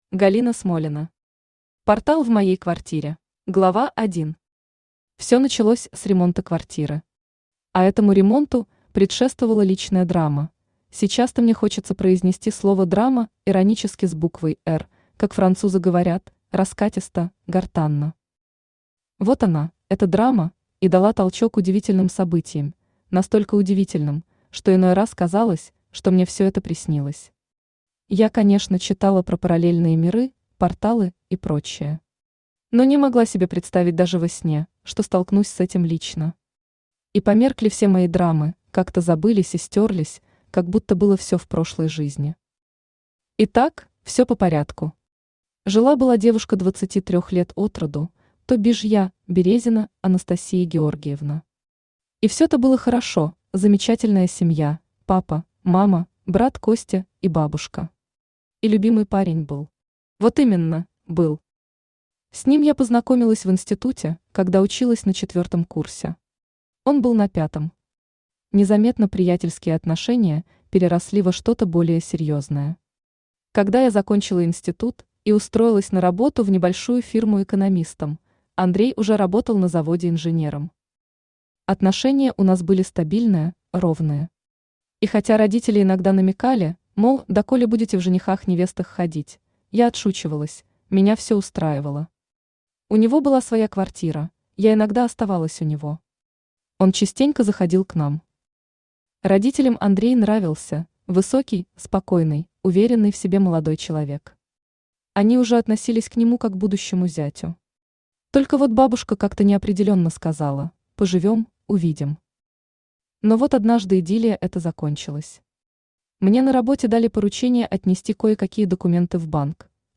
Аудиокнига Портал в моей квартире | Библиотека аудиокниг
Aудиокнига Портал в моей квартире Автор Галина Смолина Читает аудиокнигу Авточтец ЛитРес.